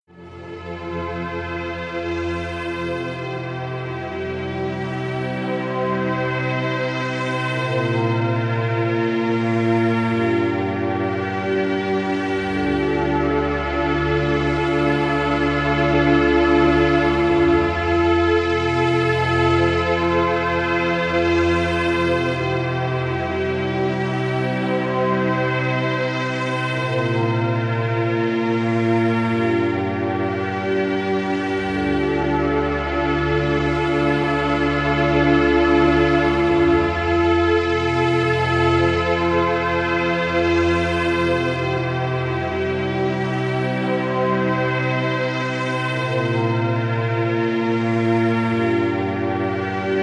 Muzyka wspaniale nadaje się do relaksu czy medytacji.